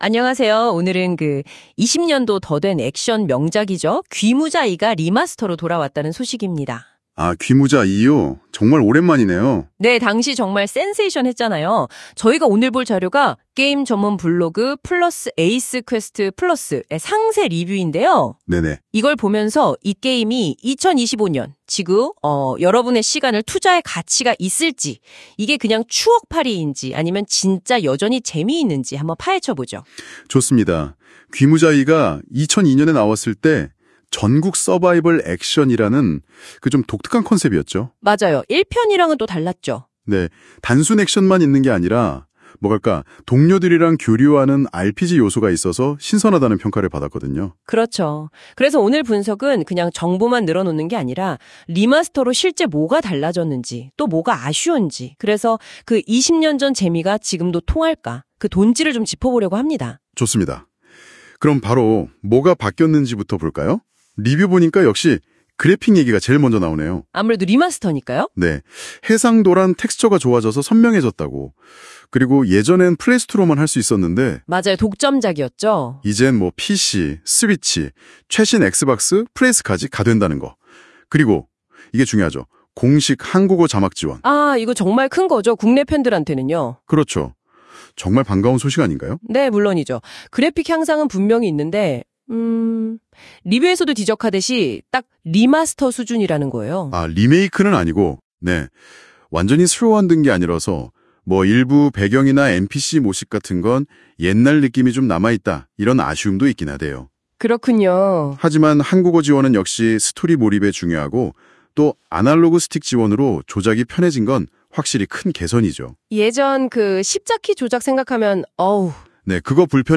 [AI 오디오로 요약본 듣기]